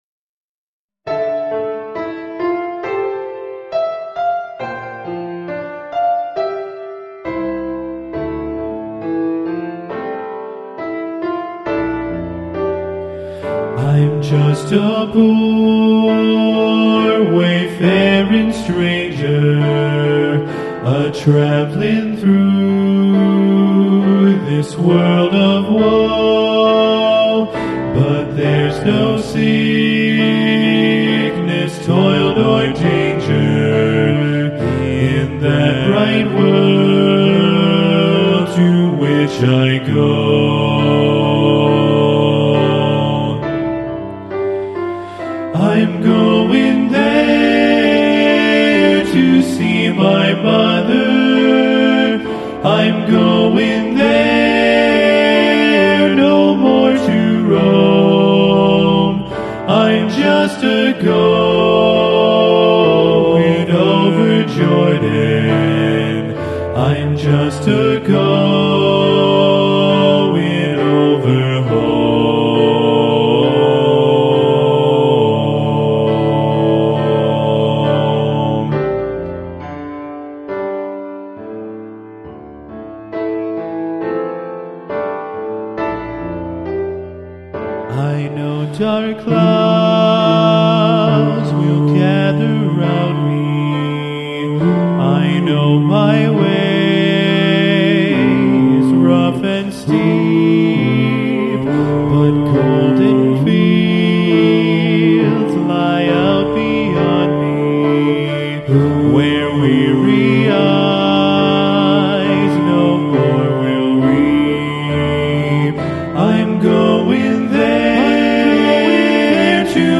Composer: Spiritual
Voicing: TTB and Piano